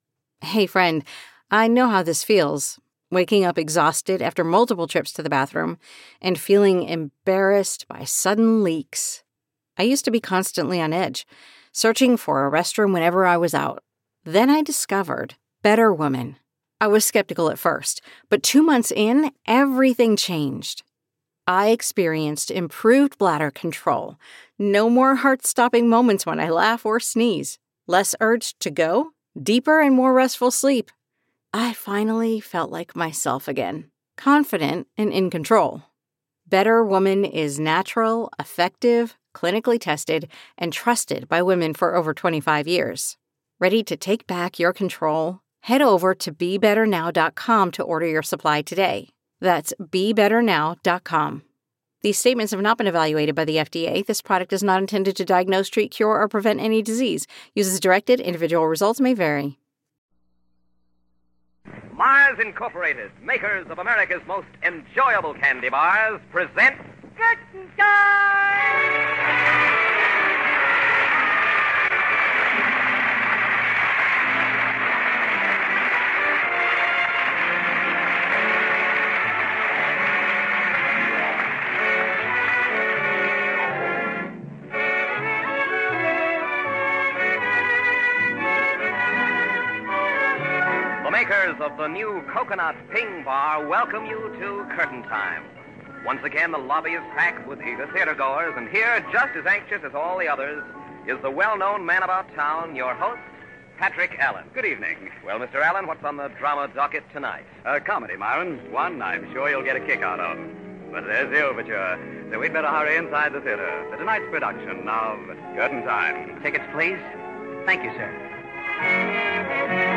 Curtain Time was a popular American radio anthology program that aired during the Golden Age of Radio. It was known for its romantic dramas and its unique presentation style that aimed to recreate the atmosphere of attending a live theater performance.Broadcast History: 1938-1939: The show first aired on the Mutual Broadcasting System from Chicago. 1945-1950: It had a much more successful run on ABC and NBC, gaining a wider audience and greater popularity. Format and Features: "Theater Atmosphere": The show used sound effects and announcements to evoke the feeling of being in a theater, with an announcer acting as an usher and reminding listeners to have their tickets ready.